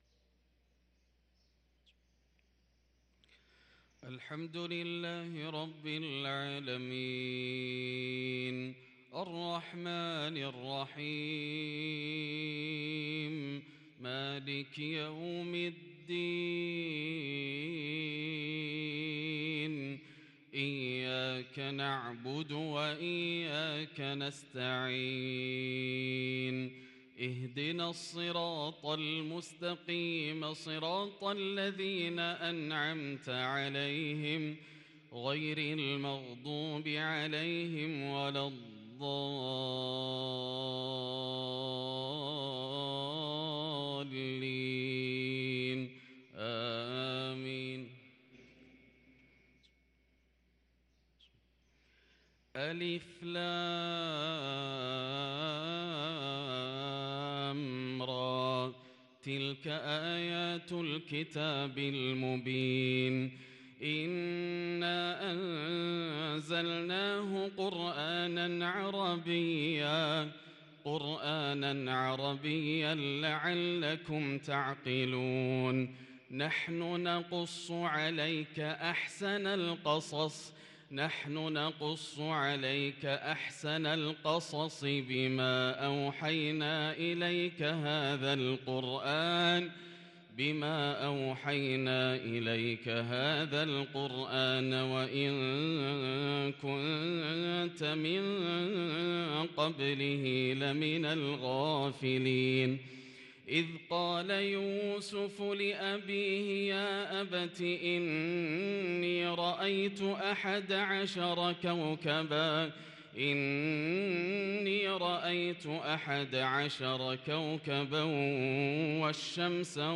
صلاة الفجر للقارئ ياسر الدوسري 2 جمادي الأول 1444 هـ